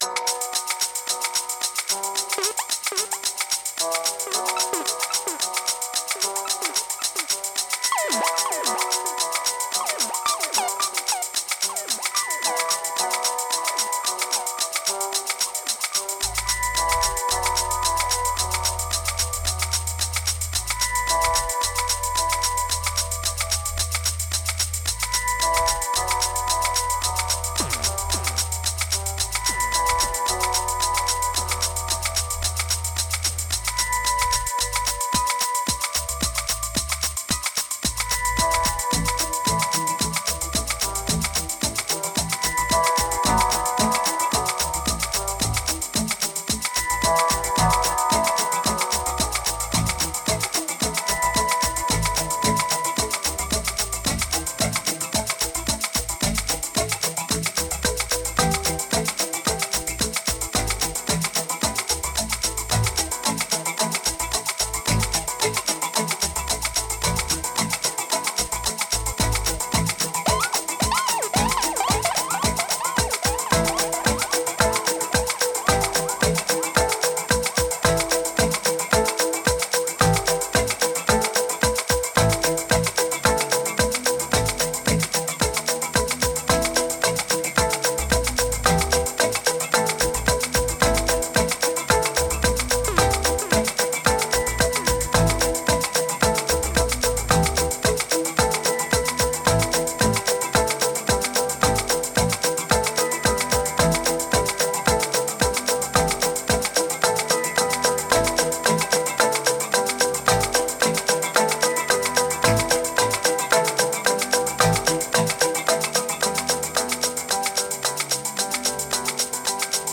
Kicks Energy Brave Formula Arpeggiator Robotic Groove Insane